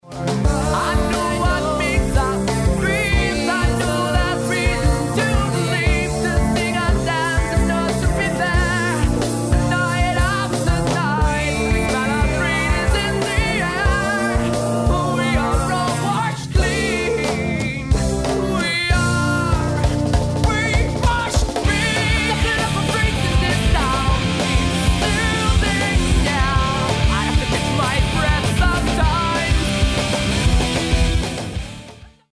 Good songs formed in power chord guitar arrangements.....